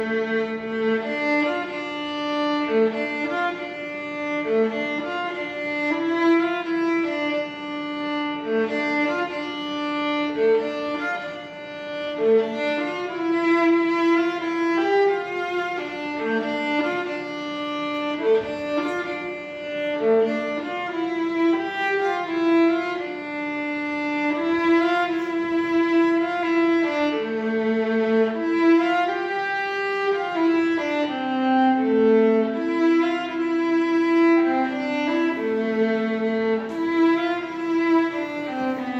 Performance, 2022.